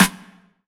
SNARE 114.wav